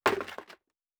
Plastic Foley Impact 2.wav